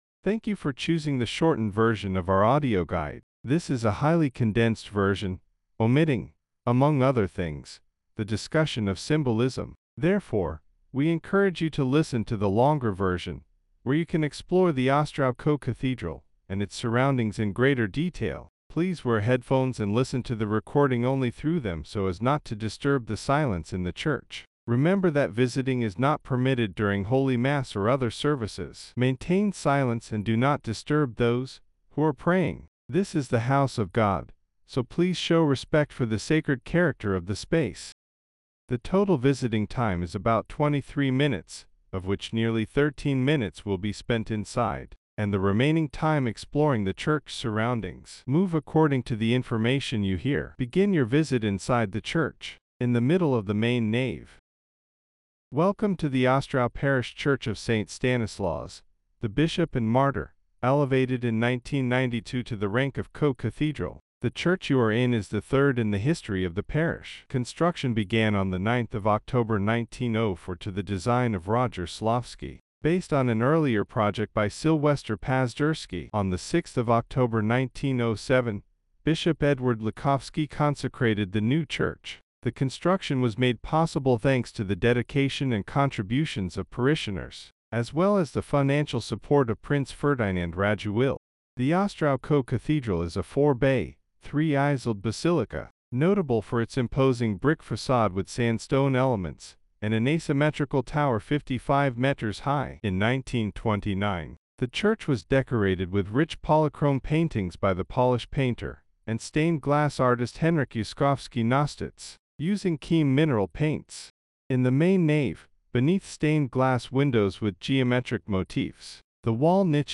[EN] Audio guide – Parafia Konkatedralna Świętego Stanisława Biskupa Męczennika w Ostrowie Wielkopolskim
Audioguide-short-EN.mp3